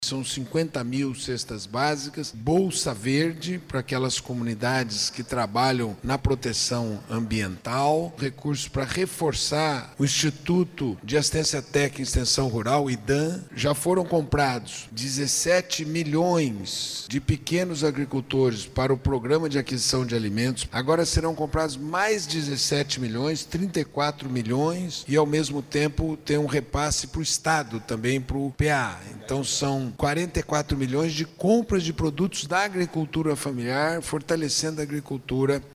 SONORA-1-ANUNCIOS-MINISTRO-AGRICULTURA-.mp3